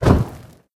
amber_reload_01.ogg